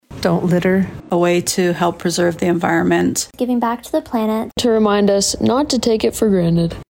MBC spoke with area residents for their thoughts on what today means to them.